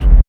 KICK.112.NEPT.wav